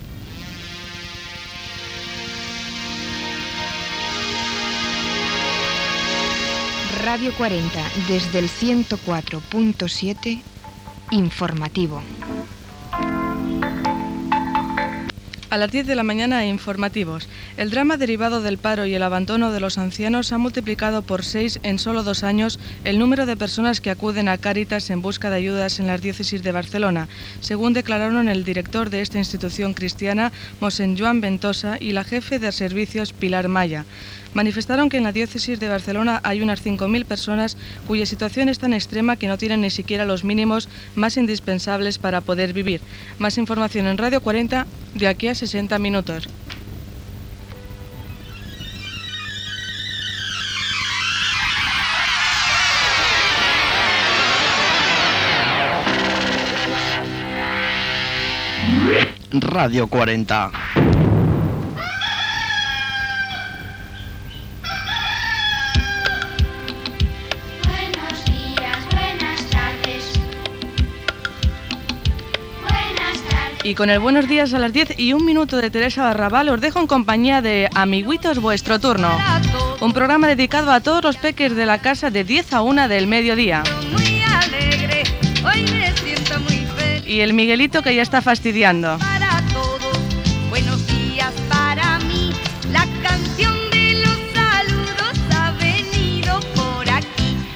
Notícia sobre els ajuts a Caritas, indicatiu, inici del programa infantil.
Informatiu